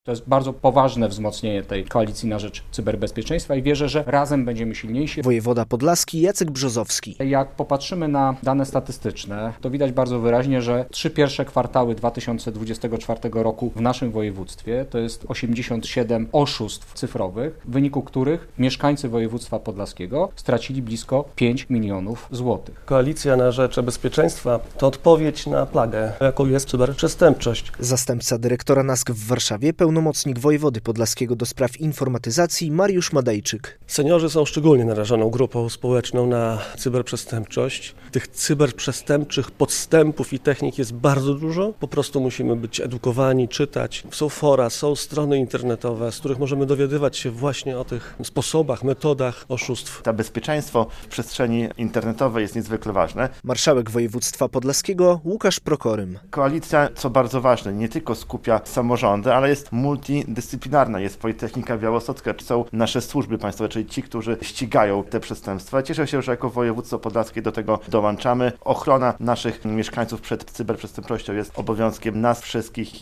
Koalicja na rzecz e-bezpieczeństwa - relacja